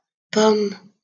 wymowa:
?/i IPA[pɔm]